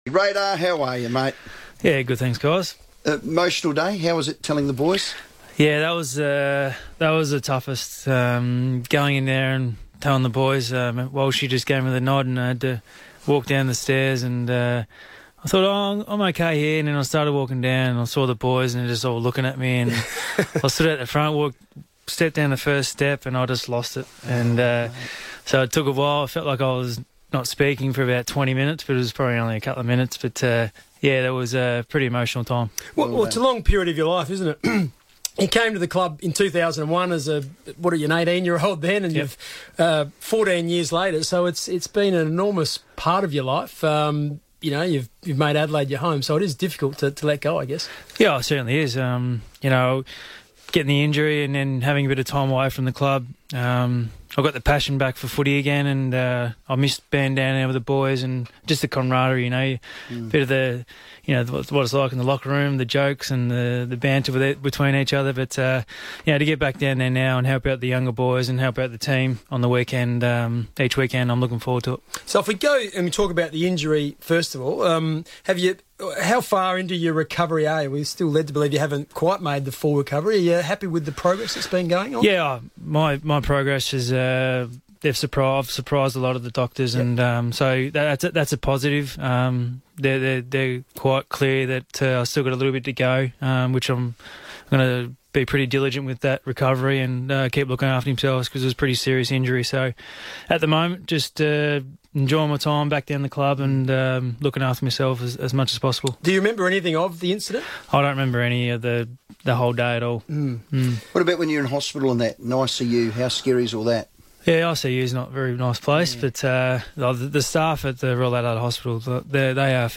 Brent Reilly joined the FIVEaa Sports Show after announcing his retirement from football